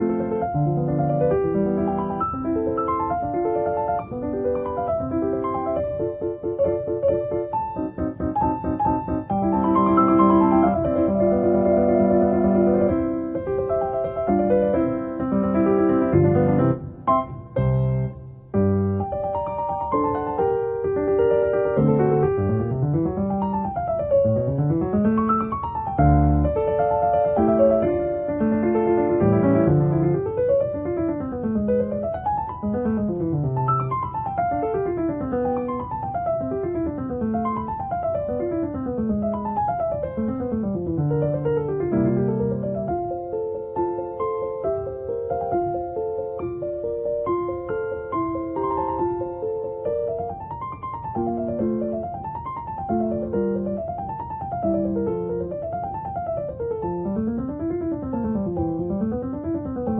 in C major